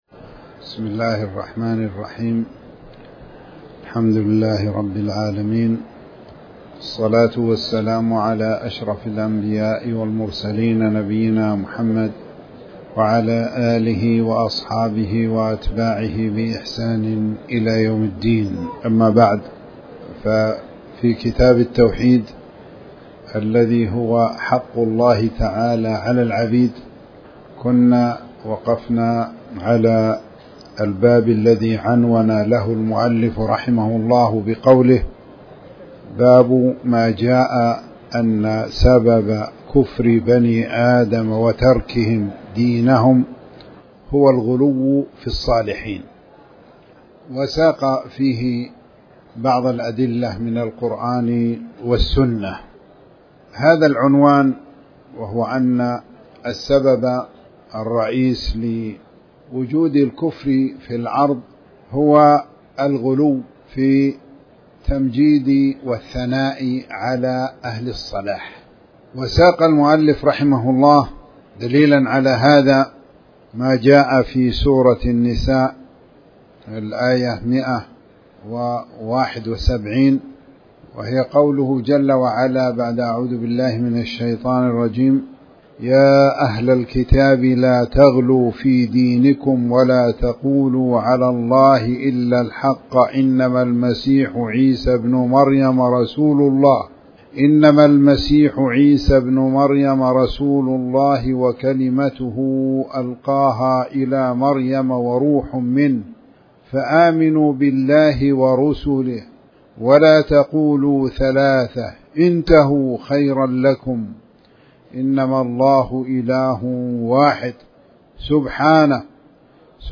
تاريخ النشر ٦ محرم ١٤٤٠ هـ المكان: المسجد الحرام الشيخ